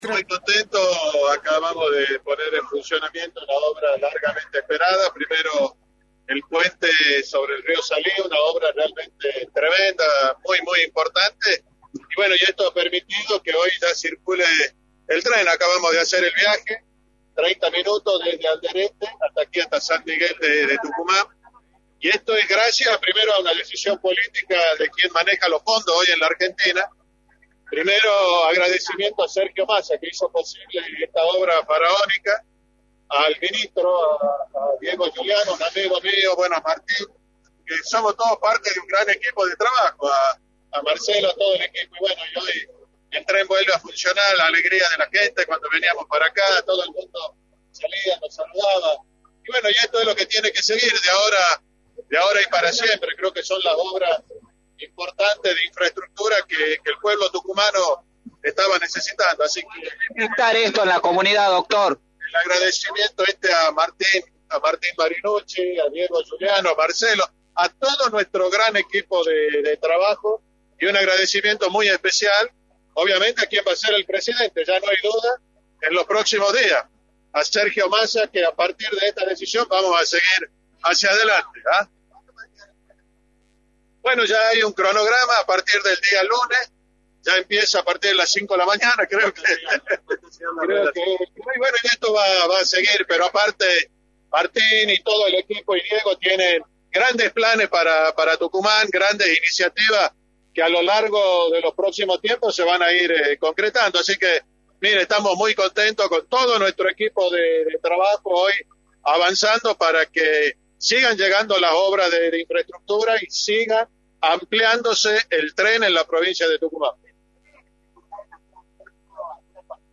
Juan Manzur, Gobernador, remarcó en Radio del Plata Tucumán, por la 93.9, las repercusiones de la inauguración de la obra del tren que conecta a Alderetes con San Miguel de Tucumán.
“Sergio Massa hizo posible esta obra faraónica, un agradecimiento muy especial a quien va a ser el presidente, ya no hay dudas, Sergio Massa, que a partir de esta decisión vamos a seguir hacia adelante” señaló Juan Manzur en entrevista para “La Mañana del Plata”, por la 93.9.